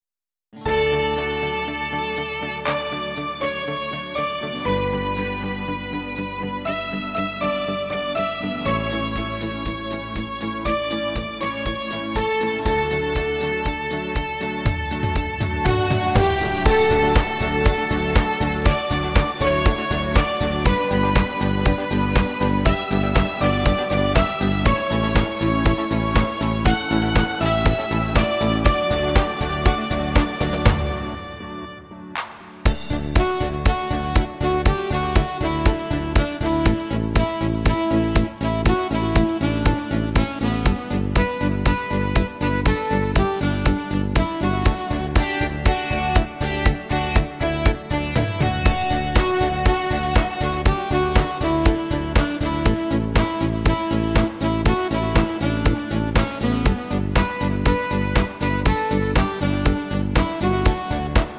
Rhythmus  Discofox
Art  Deutsch, Popschlager